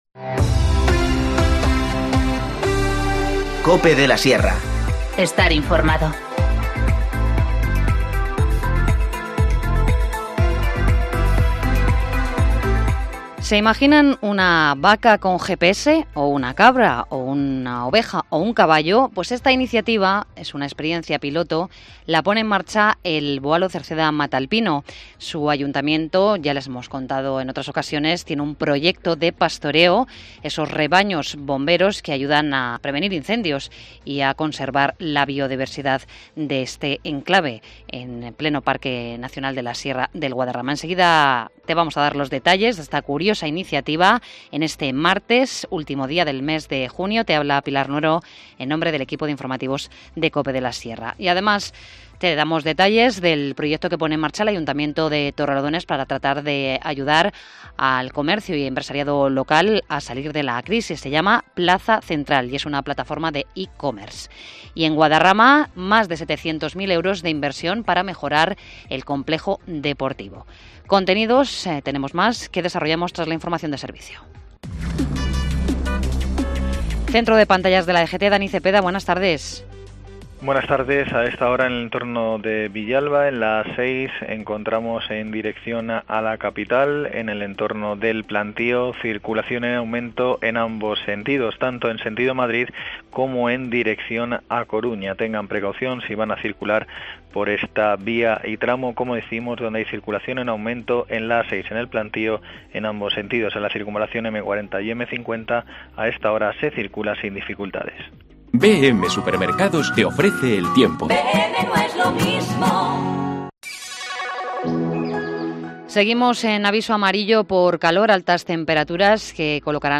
Informativo Mediodía 30 junio 14:20h